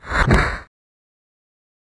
Taekwondo Roundhouse Kick sound effect for fight or game sound effects.